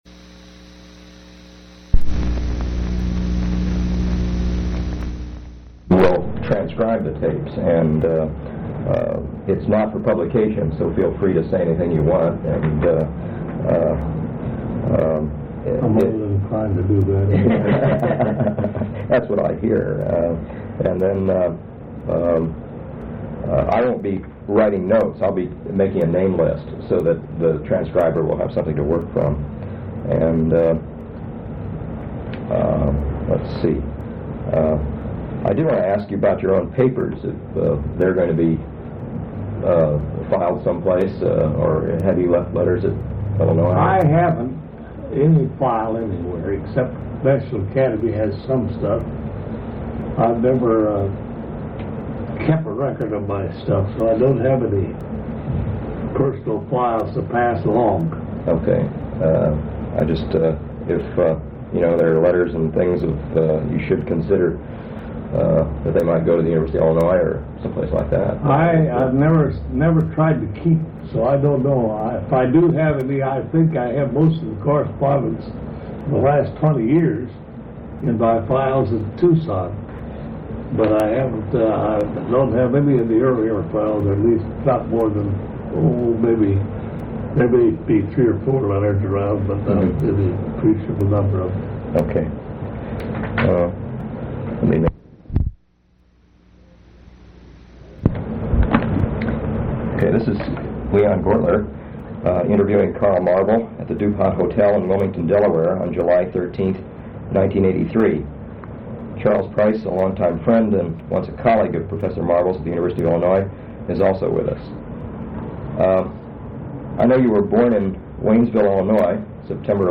Oral history interview with Carl S. Marvel